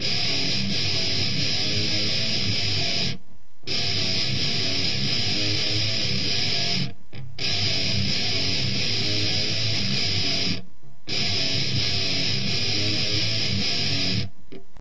描述：我想大多数是120bpm，不确定
Tag: 1 吉他 铁杆 金属 石头 RYTHEM rythum 捶打